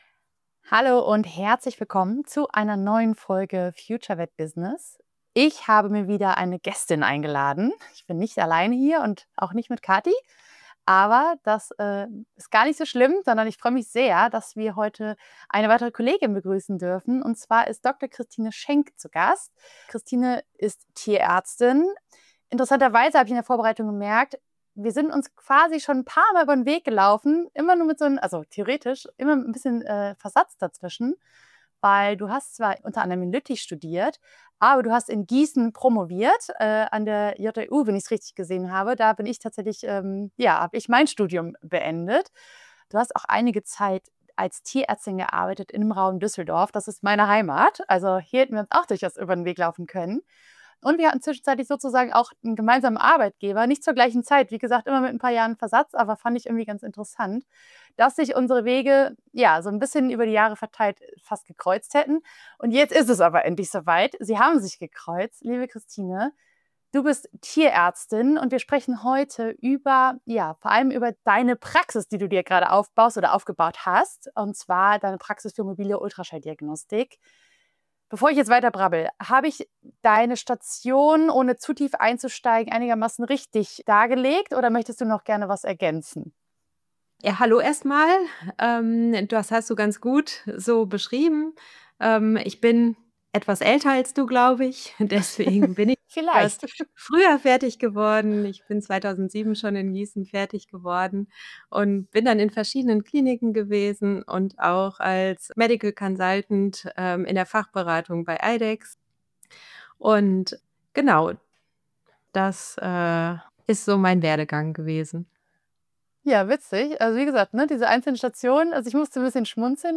im Gespräch über ihre Spezialisierung und ihre mobile Praxis ~ FutureVetBusiness Podcast